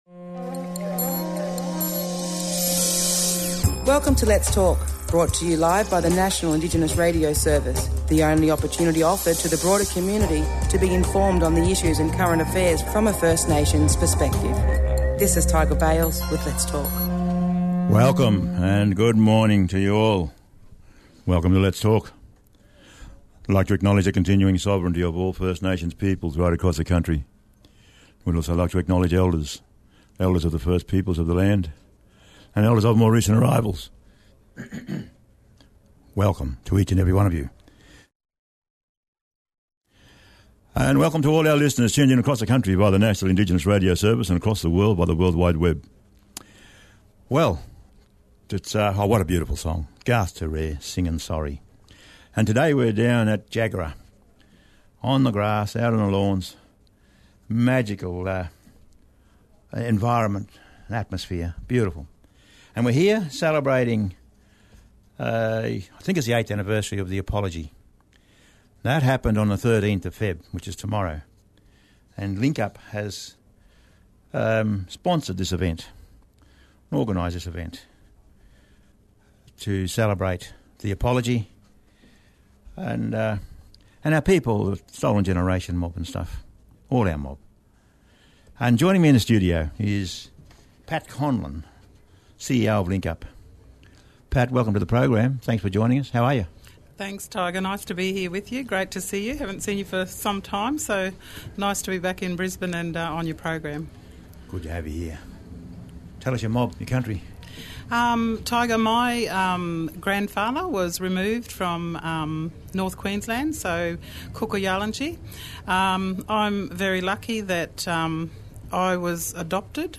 Live broadcast from Jagera Community Hall in South Brisbane to commemorate the anniversary of Kevin Rudd’s Apology speech.